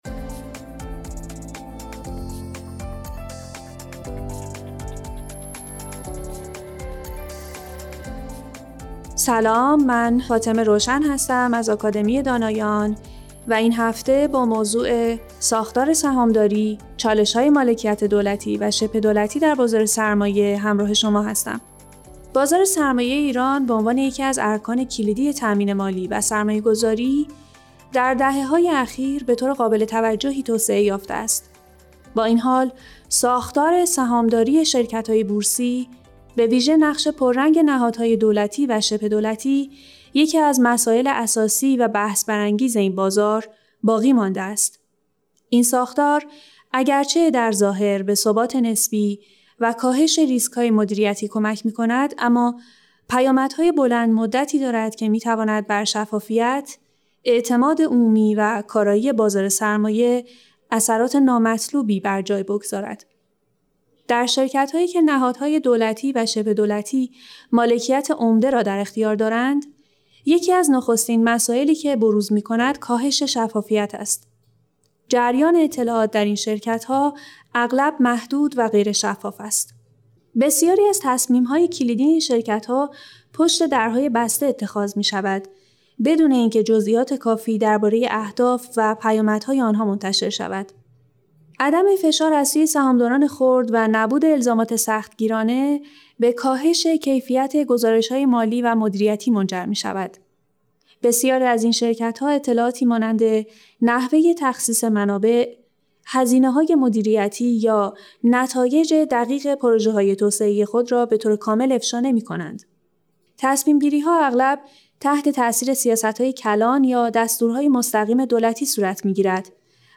فایل صوتی مناظره